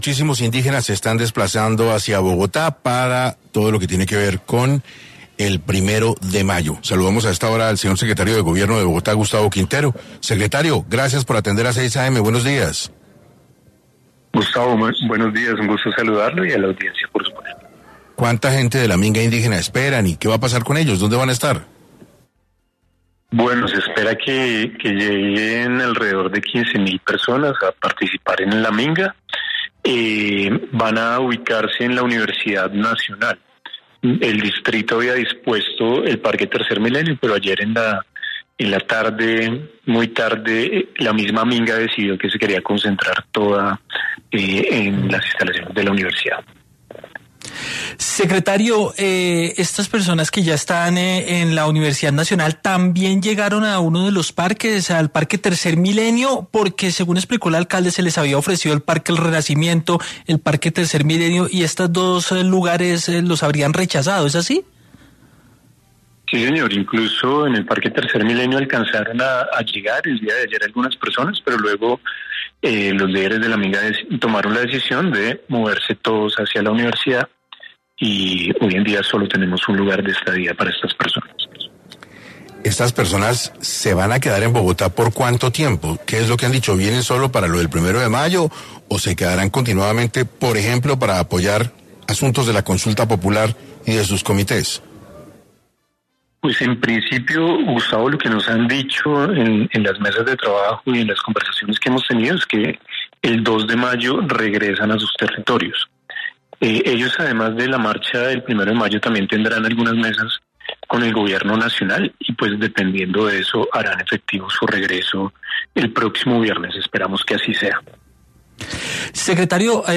En entrevista para 6AM, el secretario de Gobierno de Bogotá, Gustavo Quintero, habló sobre los planes que se tiene pensado ejecutar y cómo estos van a funcionar.